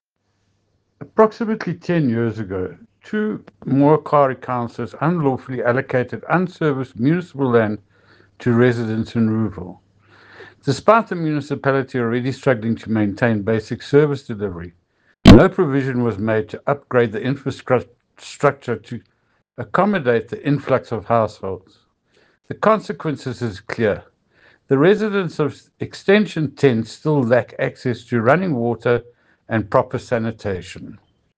English soundbite by Cllr Ian Riddle, and
Rouxville-Water-English.mp3